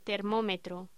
Locución: Termómetro
voz termómetro palabra sonido